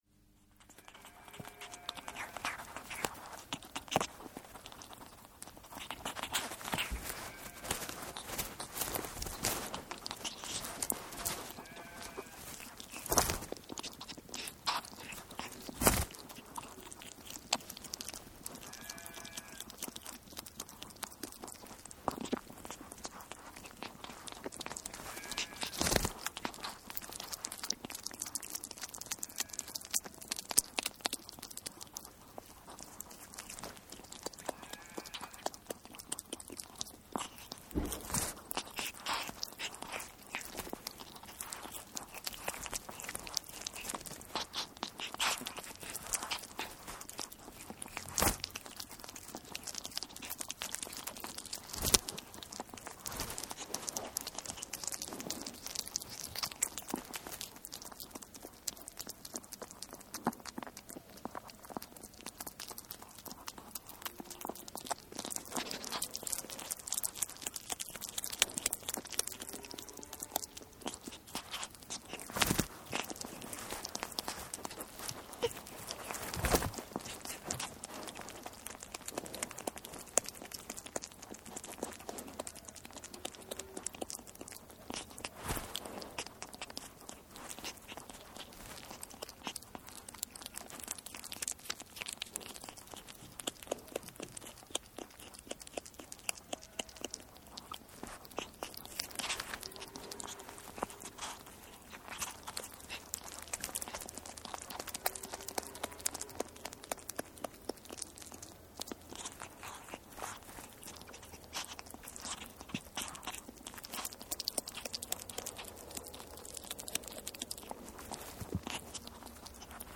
Шорох ласки, поедающей кролика